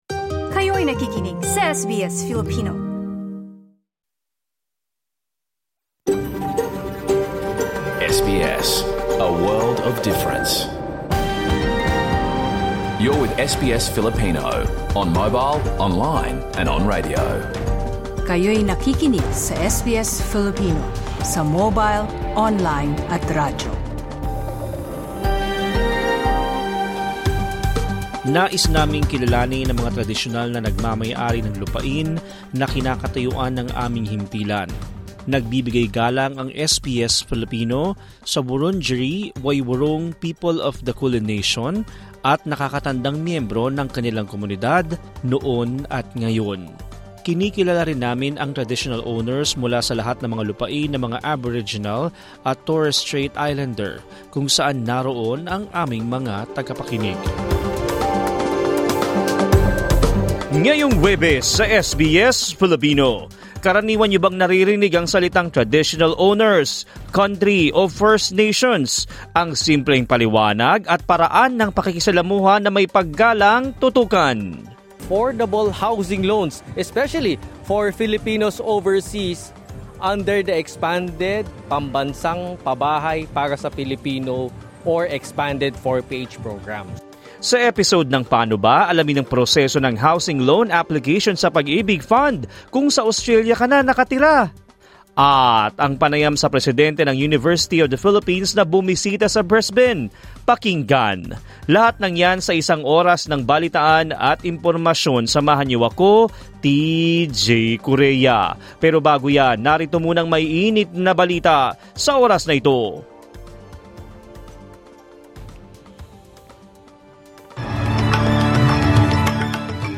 Learn the simple explanations and how to engage respectfully. In this episode of Pano Ba, find out how the housing loan application process works with the Pag-IBIG Fund if you are now living in Australia. Plus an interview with the president of the University of the Philippines, who visited Brisbane to promote education and research.